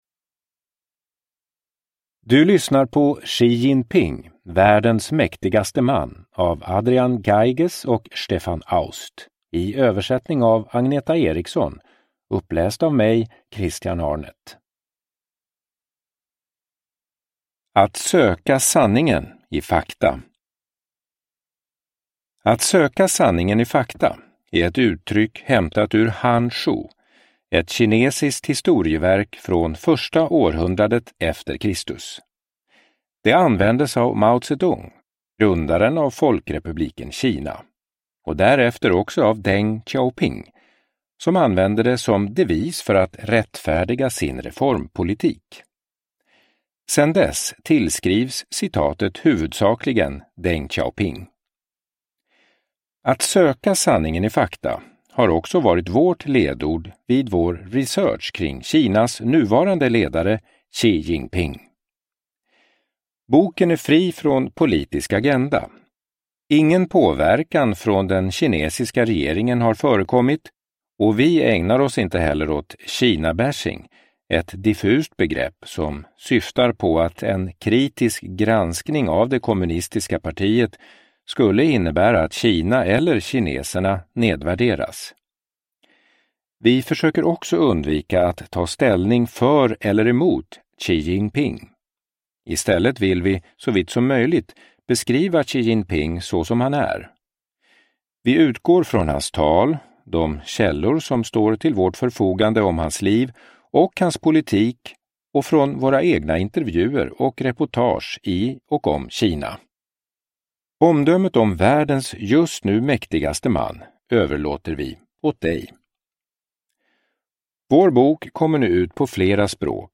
Xi Jinping : världens mäktigaste man – Ljudbok